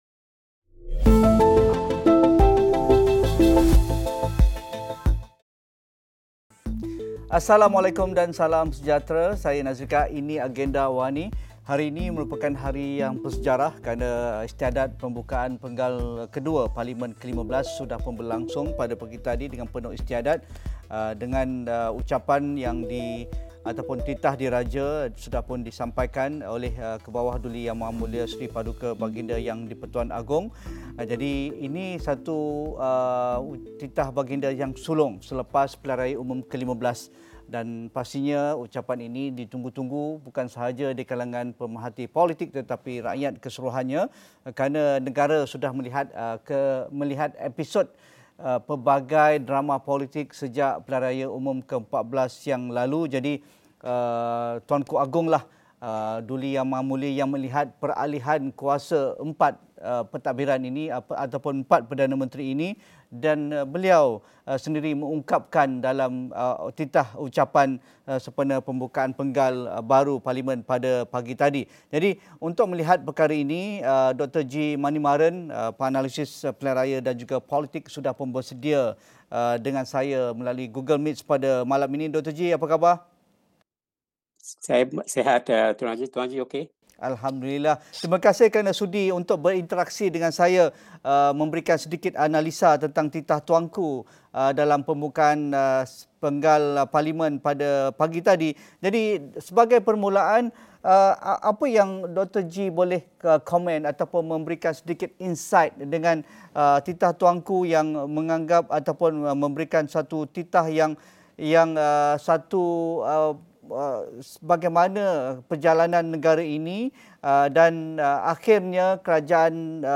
Yang di-Pertuan Agong bertitah muafakat dalam kalangan parti Kerajaan Perpaduan membolehkan negara menjana pertumbuhan ekonomi dan kesejahteraan rakyat selain dapat mewujudkan kestabilan politik. Diskusi 8.30 malam.